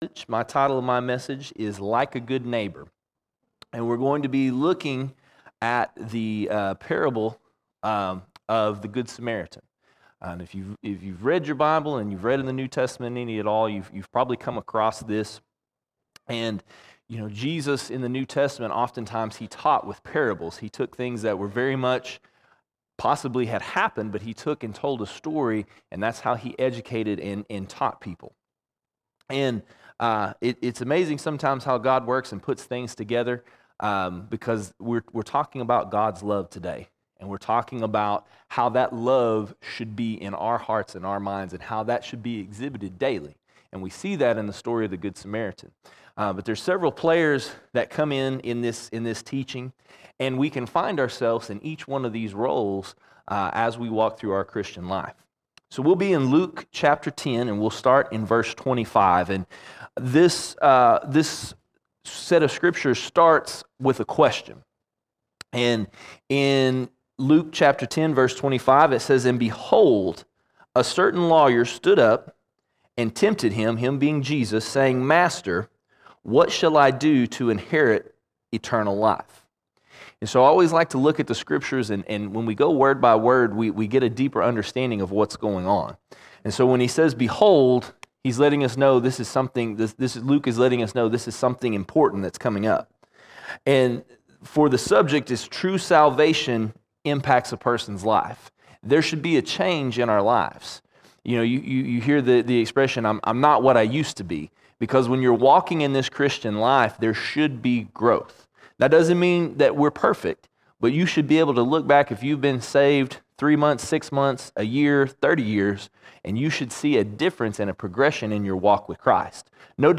22 January 2024 Series: Sunday Sermons Topic: fruit of the Spirit All Sermons Like A Good Neighbor Like A Good Neighbor When people mistreat you, can you be a good neighbor?